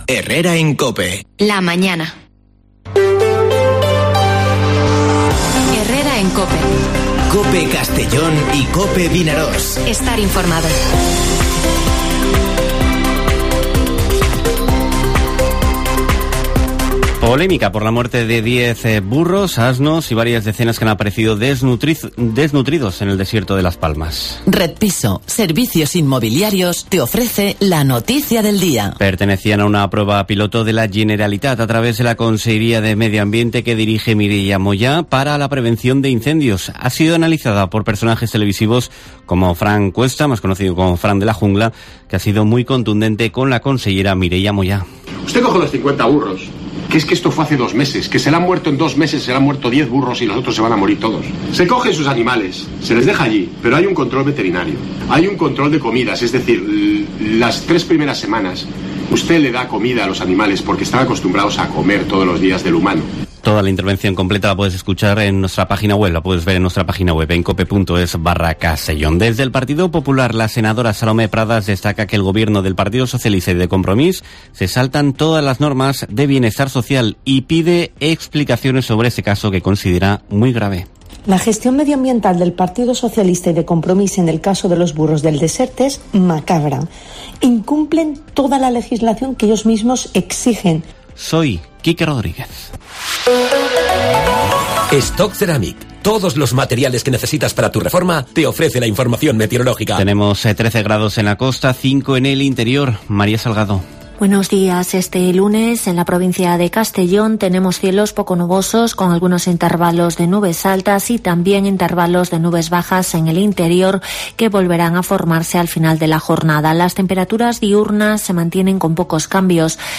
Informativo Herrera en COPE en la provincia de Castellón (25/10/2021)